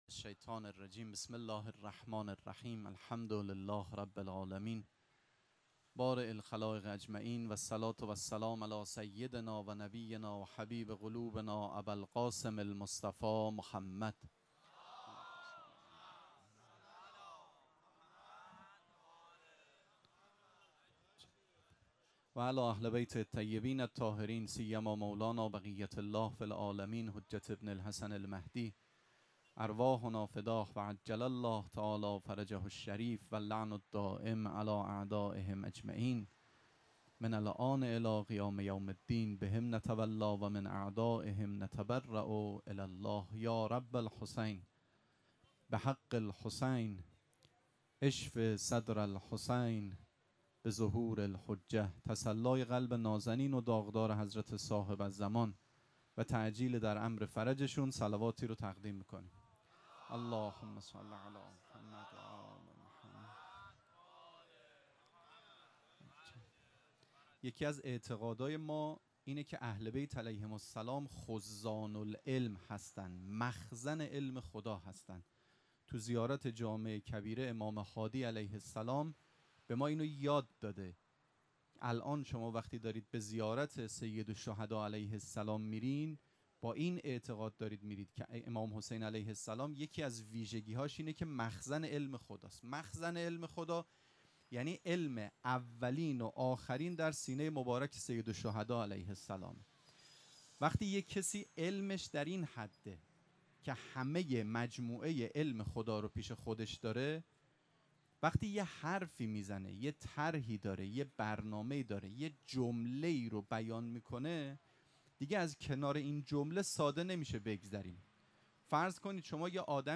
سخنرانی
شب اول مراسم عزاداری اربعین حسینی ۱۴۴۷ جمعه ۱۷ مرداد ۱۴۰۴ | ۱۴ صفر ۱۴۴۷ موکب ریحانه الحسین سلام الله علیها